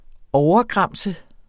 Udtale [ ˈɒwʌˌgʁɑmˀsə ]